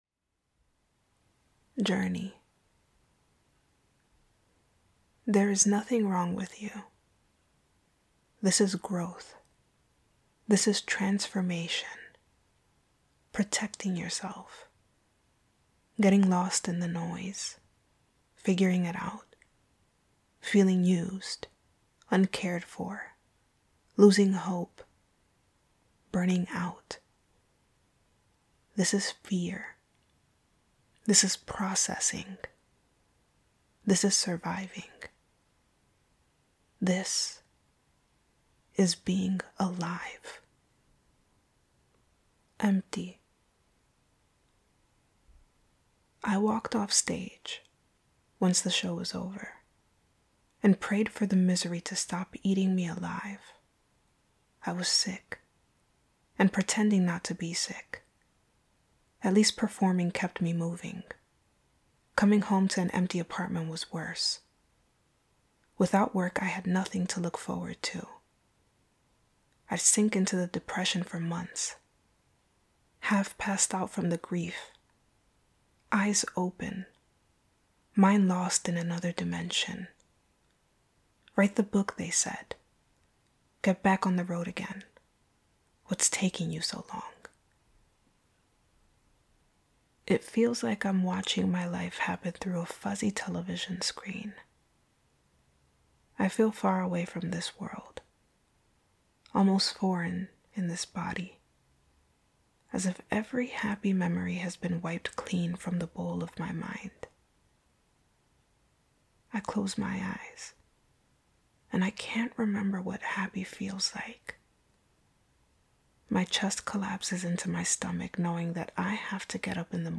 [Sleep-Aid]
poetry reading